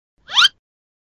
Hiệu ứng âm thanh SLIP mp3 - Tải hiệu ứng âm thanh để edit video